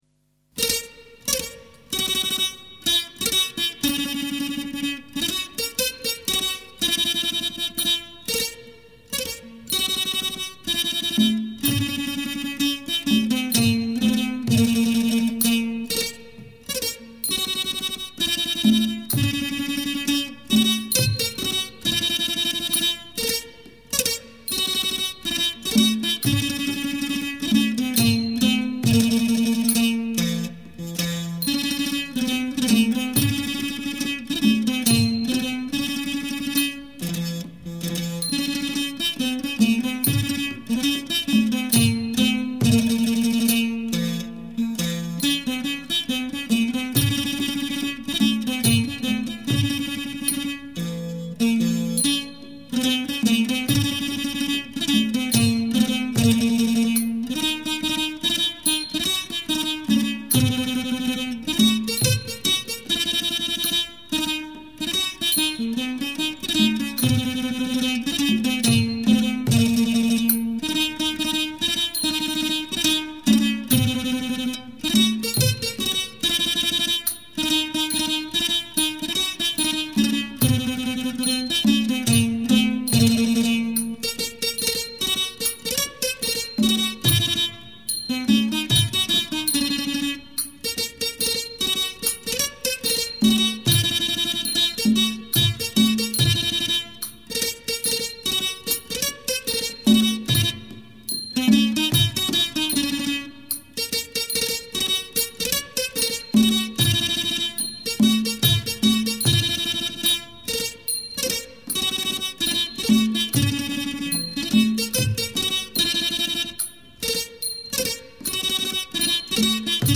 [3/5/2008]Thai Classical Music (Instrumental)
Thai Classical Music